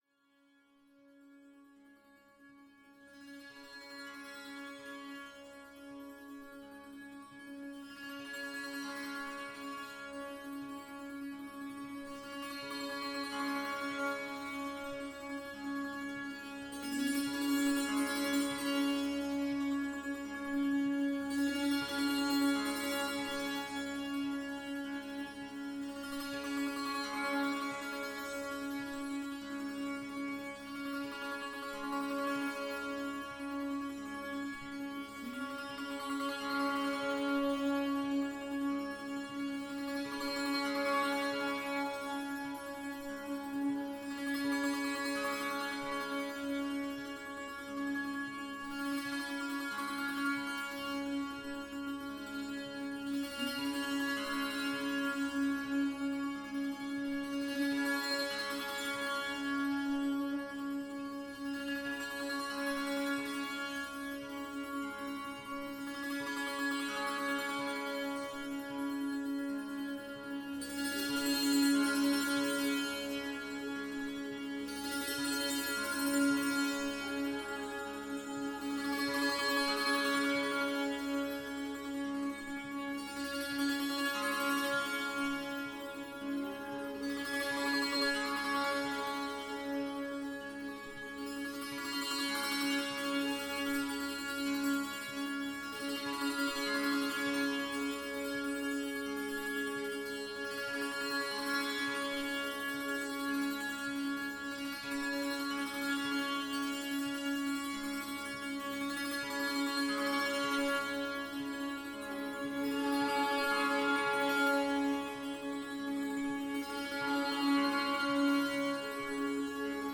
Ich spiele immer – auf das Thema eingestimmt – im Vorfeld und nehme die Klänge auf.
Klangprobe-Neumond.mp3